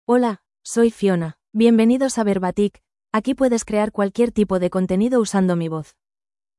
Fiona — Female Spanish (Spain) AI Voice | TTS, Voice Cloning & Video | Verbatik AI
Fiona is a female AI voice for Spanish (Spain).
Voice sample
Female
Fiona delivers clear pronunciation with authentic Spain Spanish intonation, making your content sound professionally produced.